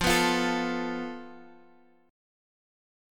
FMb5 chord